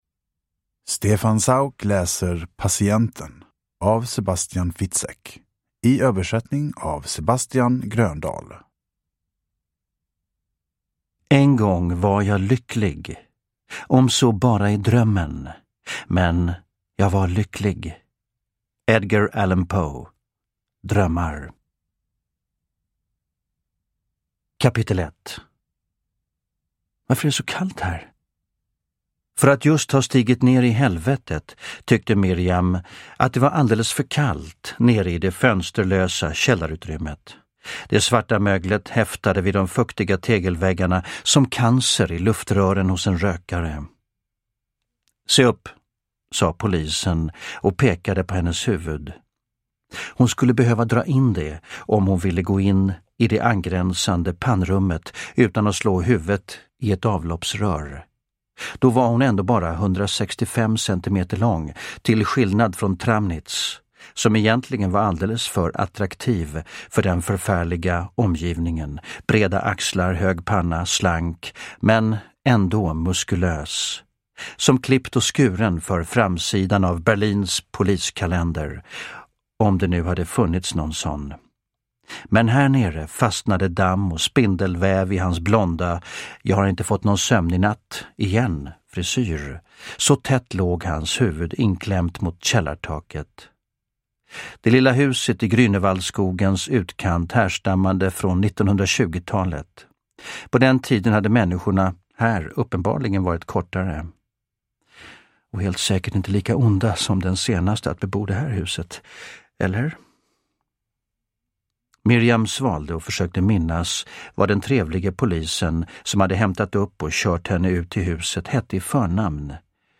Patienten – Ljudbok – Laddas ner
Uppläsare: Stefan Sauk